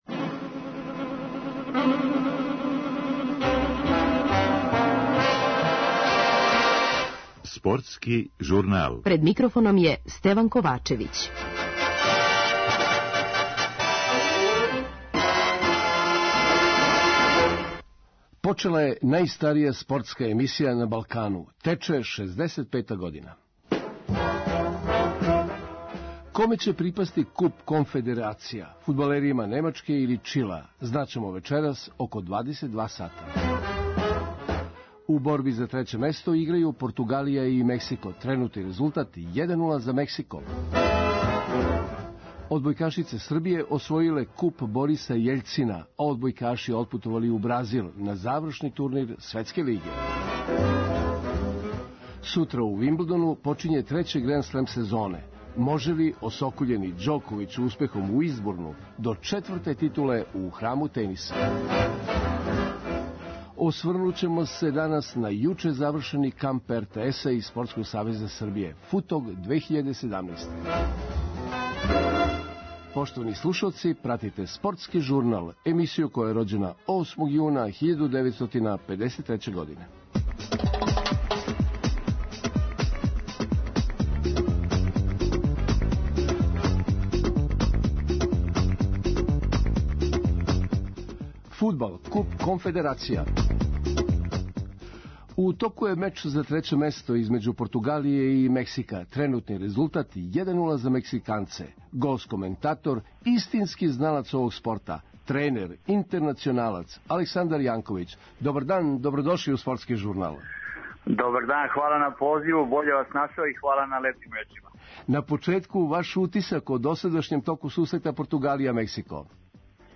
Аудио подкаст Радио Београд 1